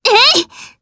Frey-Vox_Attack1_jp.wav